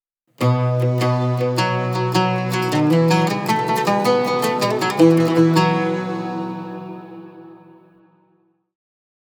مستوحاة من مقام السيكا لتعكس تاريخ المدينة المنورة
الإشعارات والتنبيهاتنغمات قصيرة تُستخدم في الحرم الجامعي للتنبيهات والإعلانات
Oud version 3.wav